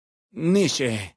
Category:Dead Horses pidgin audio samples Du kannst diese Datei nicht überschreiben.